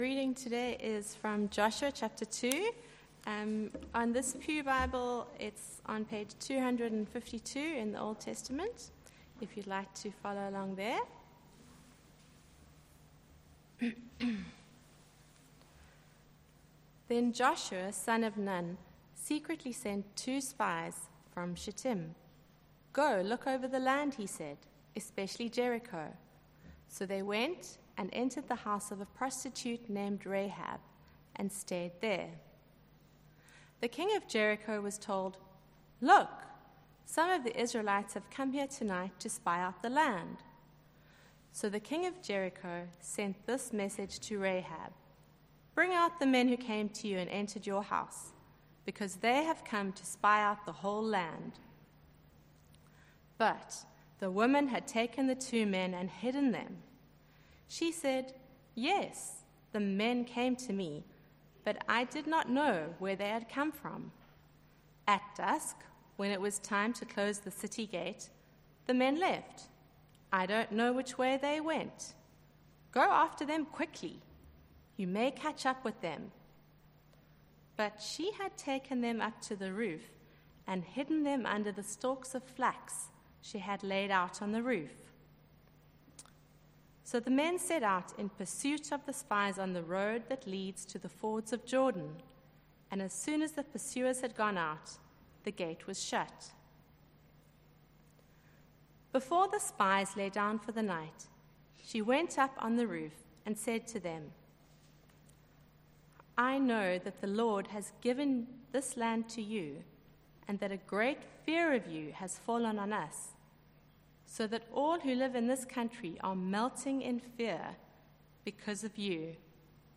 Rahab: Grace|Holy Trinity Church Gardens Cape Town